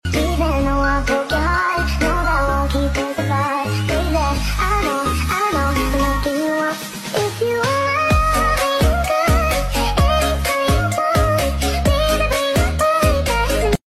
sound effects free download
You Just Search Sound Effects And Download. tiktok funny sound hahaha Download Sound Effect Home